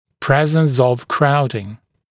[‘prezns əv ‘kraudɪŋ][‘прэзнс ов ‘краудин]наличие скученности